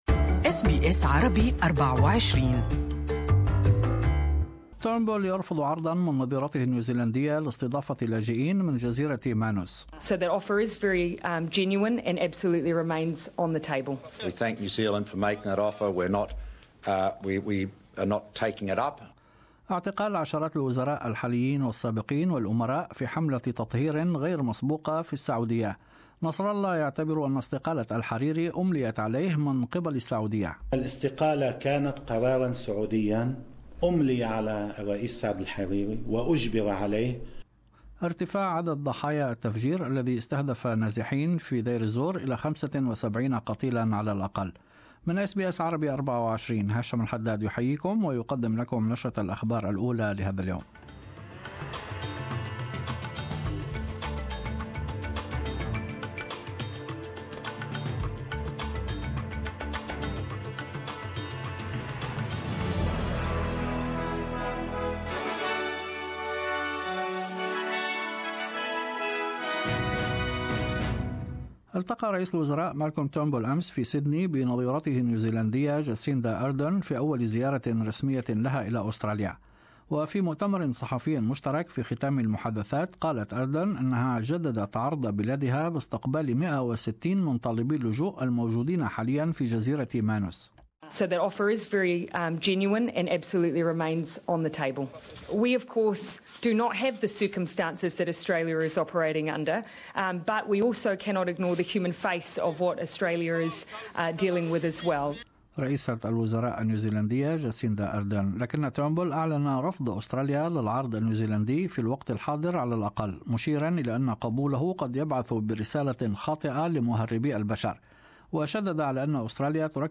In this bulletin ... ** Politicians, business leaders and sportspeople among those named in a leak of millions of financial papers ** Sacked Catalan leader Carles Puigdemont (KAR-lez POOJ-deh-mon) turns himself in to Belgian police and ** Tim Cahill cleared to fly to Honduras for the Socceroos' World Cup qualifiers